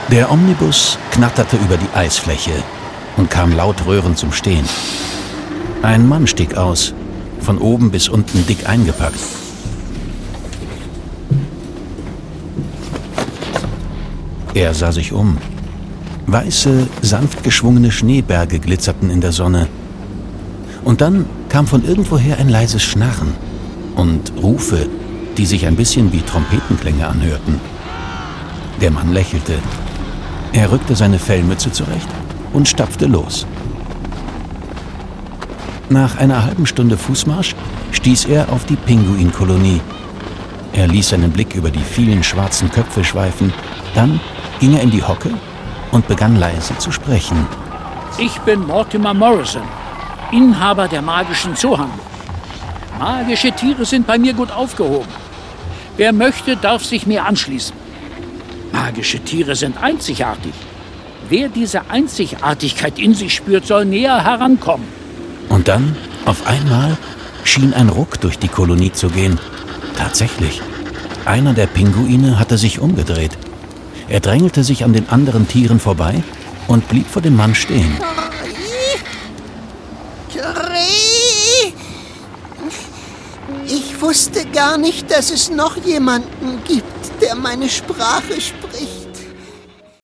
01: Die Schule der magischen Tiere - Margit Auer - Hörbuch